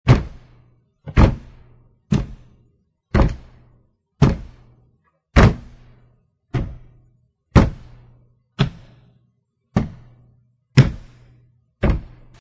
Download Stomp sound effect for free.
Stomp